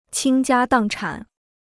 倾家荡产 (qīng jiā dàng chǎn): to lose a family fortune (idiom).